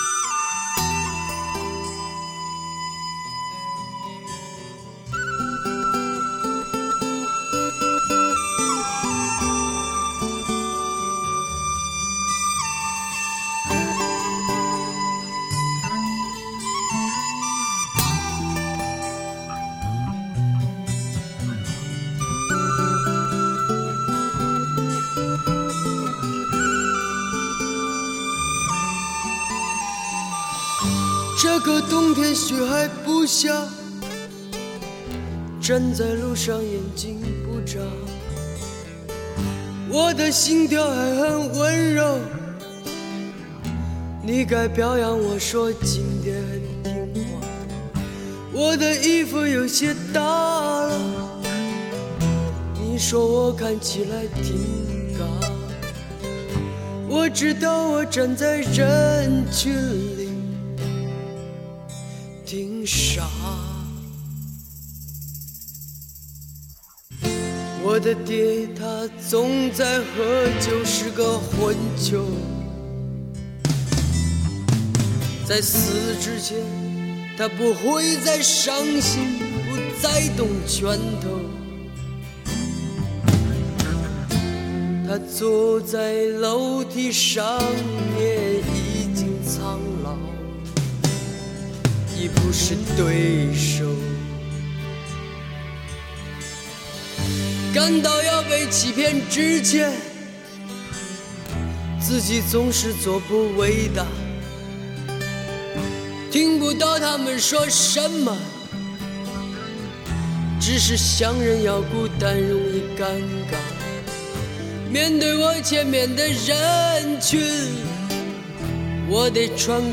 中国人世界的摇滚乐精选